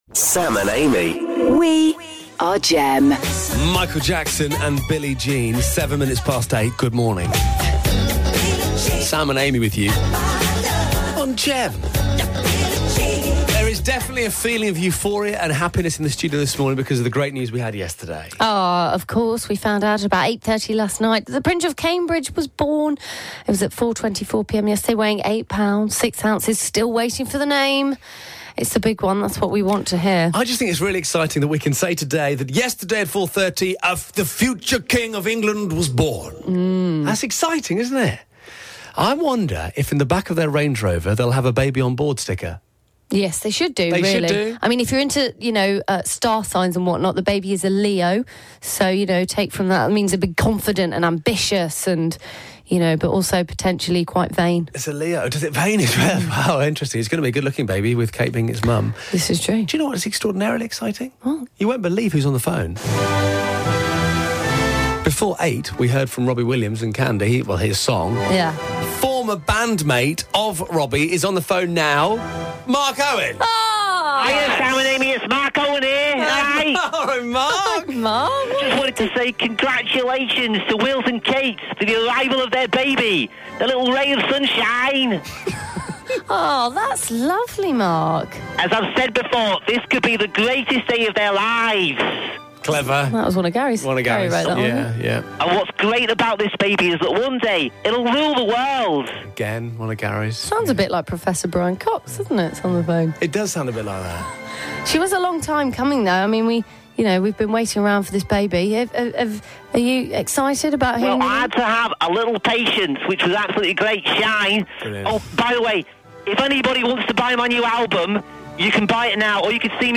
It was a very exciting morning on the show today, with numerous amazing celebrities calling up with congratulatory messages for William and Kate... all there voices did sound a little familiar though.